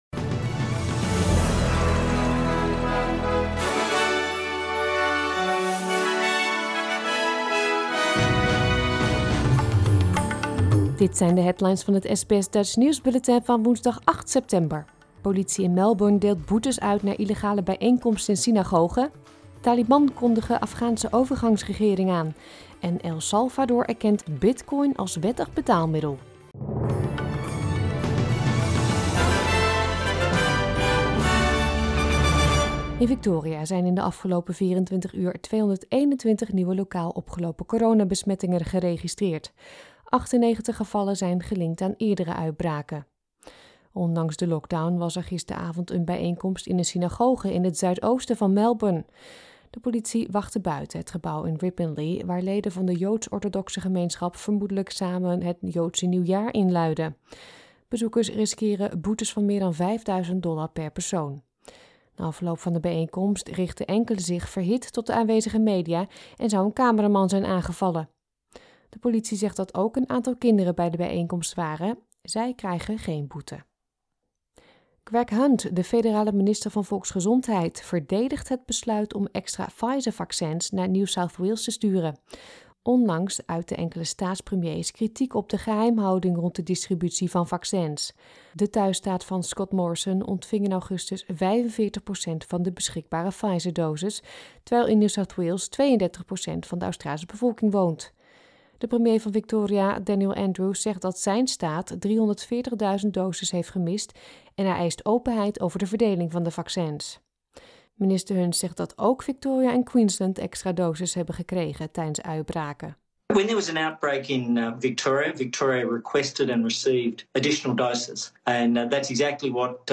Nederlands/Australisch SBS Dutch nieuwsbulletin van woensdag 8 september 2021